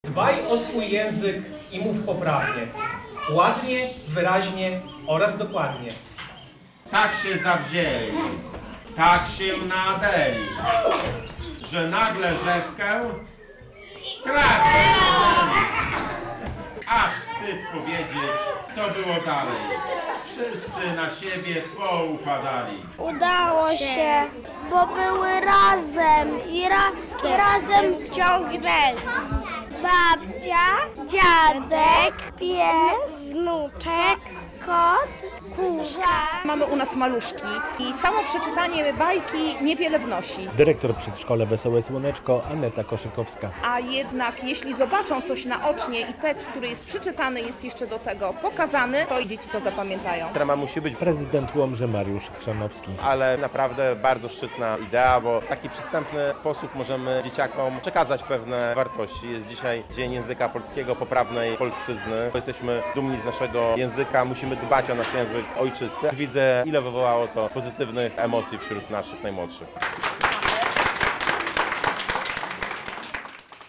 Dzień Języka Ojczystego w łomżyńskim przedszkolu - relacja